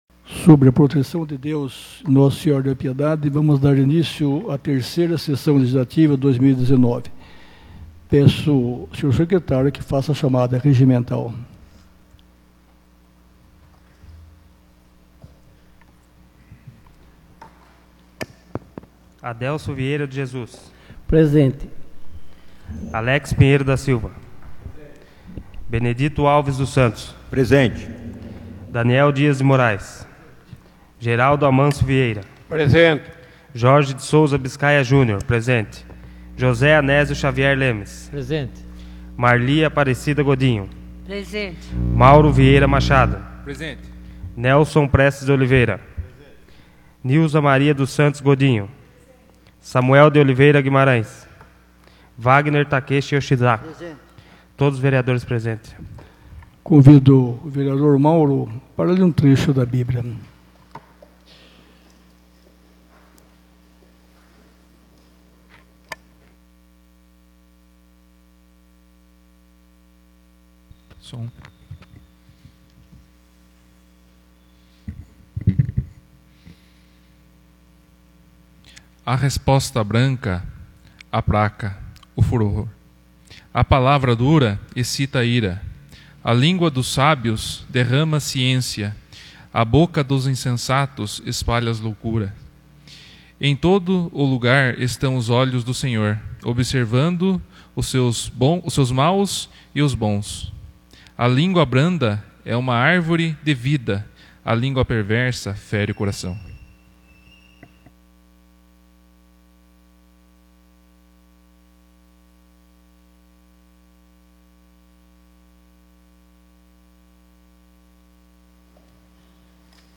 3ª Sessão Ordinária de 2019